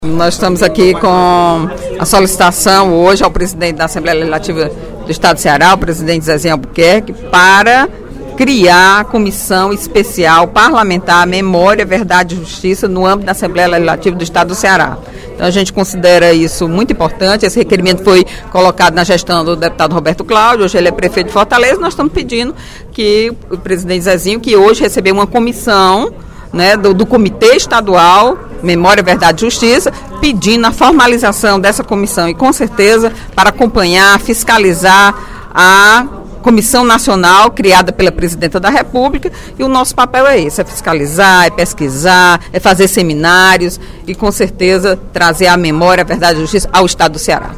No primeiro expediente da sessão plenária desta quarta-feira (27/03), a deputada Eliane Novais (PSB) lembrou o Dia Internacional do Direito à Verdade sobre Graves Violações de Direitos Humanos e pela Dignidade das Vítimas, comemorado no último dia 24.